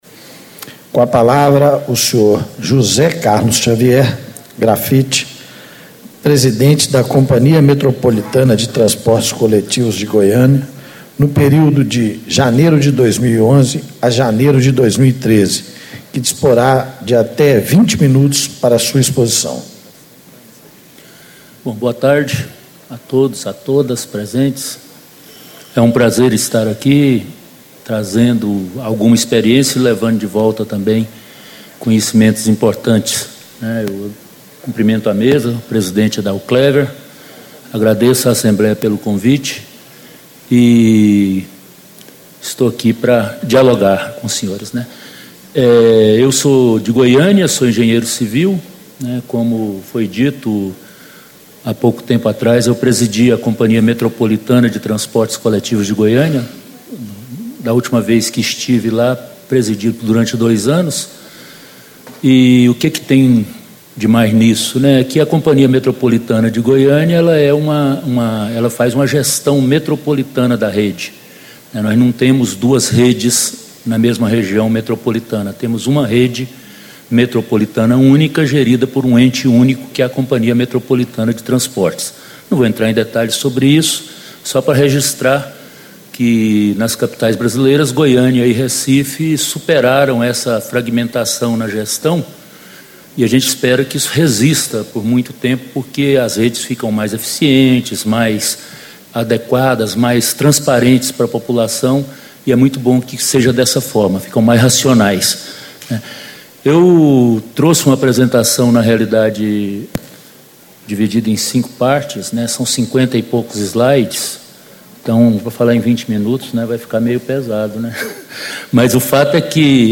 Encontro Estadual do Fórum Técnico Mobilidade Urbana - Construindo Cidades Inteligentes
Discursos e Palestras